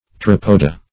Pteropoda \Pte*rop"o*da\, n. pl. [NL.] (Zool.)